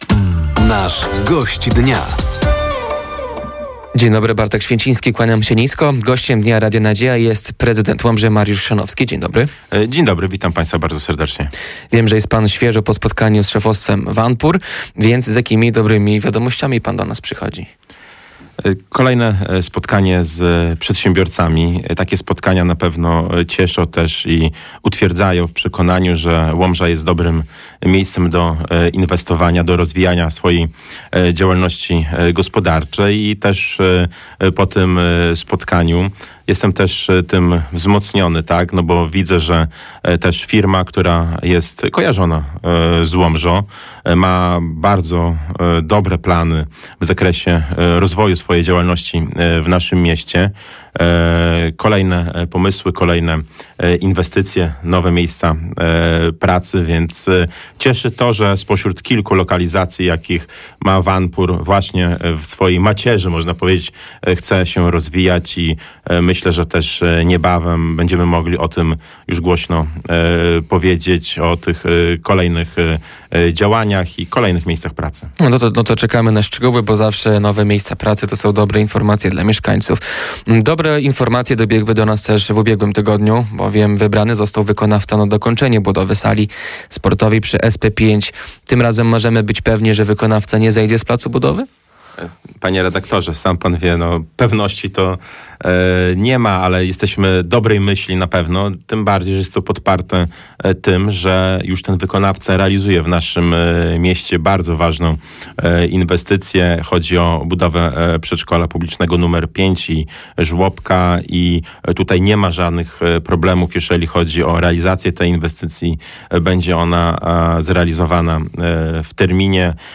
Gościem Dnia Radia Nadzieja był prezydent Łomży, Mariusz Chrzanowski. Tematem rozmowy było między innymi dokończenie budowy sali sportowej przy Szkole Podstawowej nr 5, szansa na nowe miejsca pracy w mieście oraz współpraca z nowym rządem i nową poseł Alicją Łepkowską-Gołaś z Koalicji Obywatelskiej.